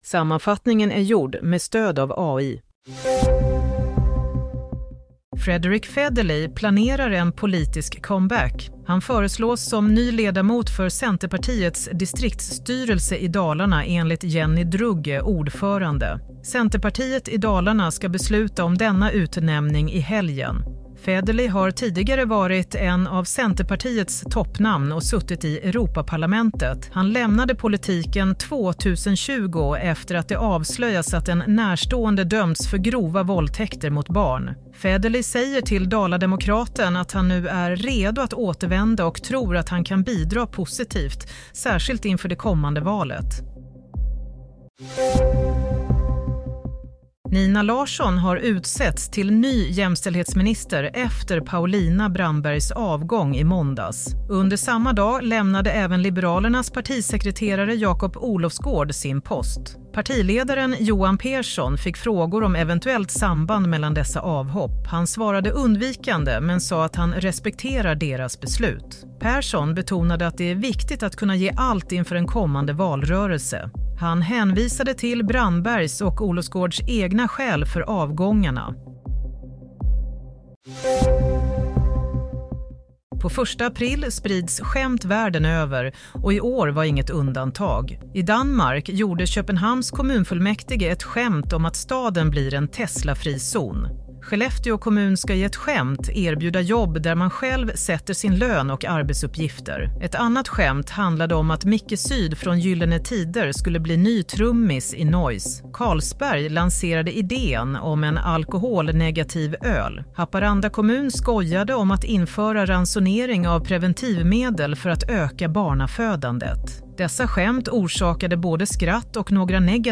Play - Nyhetssammanfattning – 1 april 16:00
Sammanfattningen av följande nyheter är gjorde med stöd av AI.